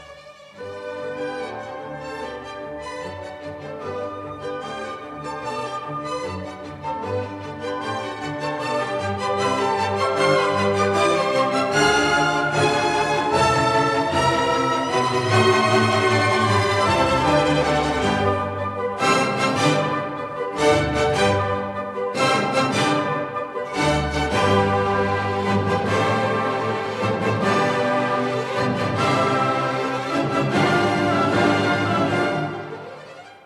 A modulating bridge and theme 2 continues the spirited tempo and fanfare.